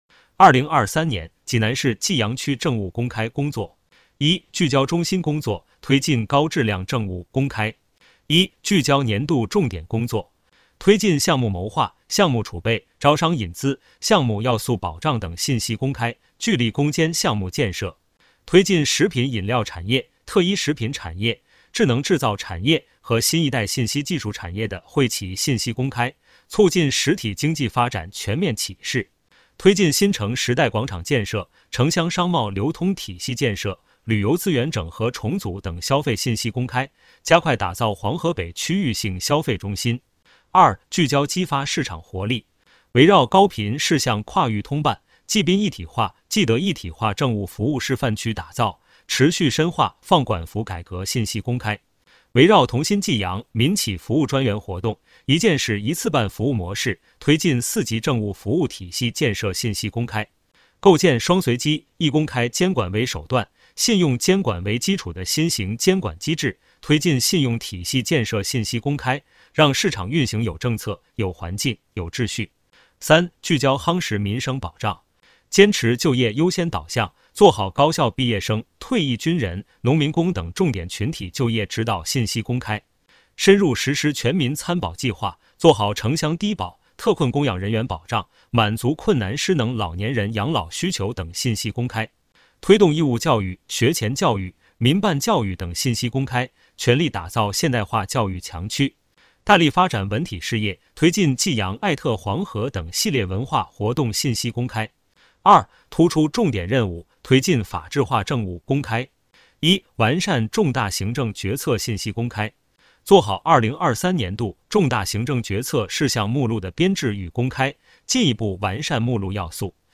【有声朗读】2023年济南市济阳区政务公开工作要点